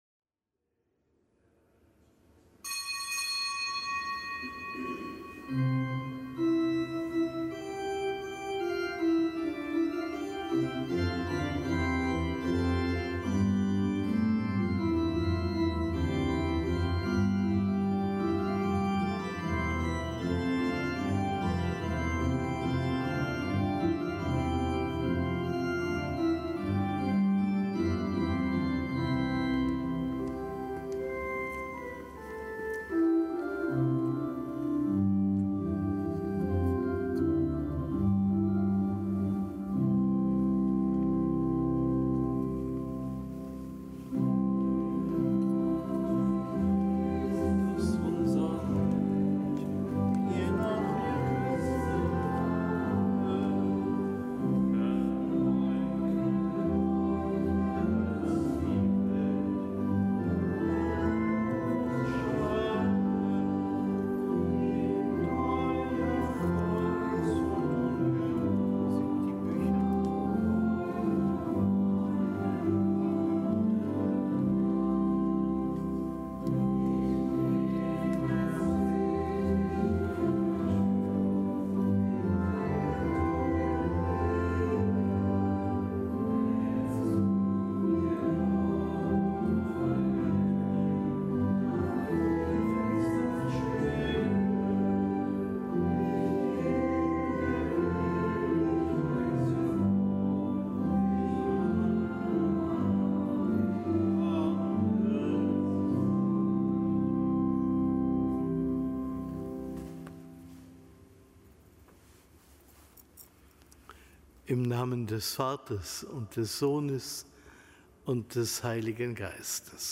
Kapitelsmesse aus dem Kölner Dom am Donnerstag der zweiundzwanzigsten Woche im Jahreskreis, Gedenktag des Heiligen Swidbert, Bischof und Glaubensbote am Niederrhein.
Zelebrant: Weihbischof Rolf Steinhäuser.